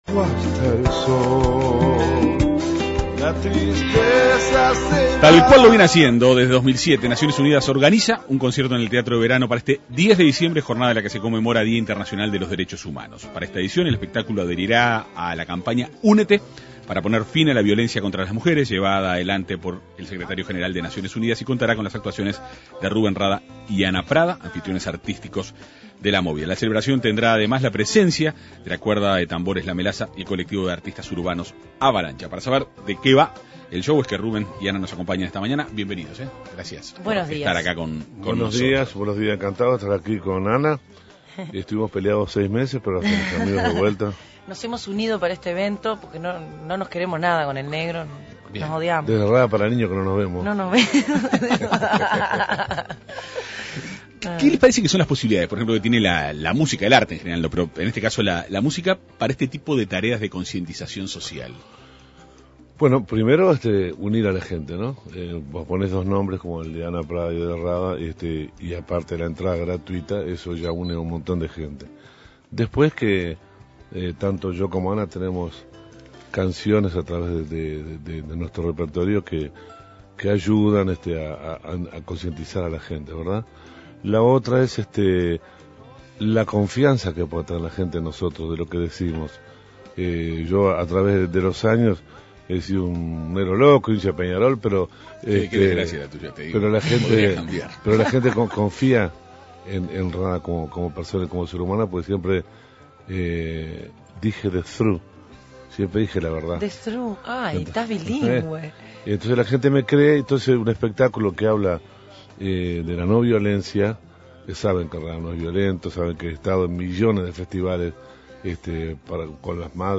Los artistas dialogaron en la Segunda Mañana de En Perspectiva.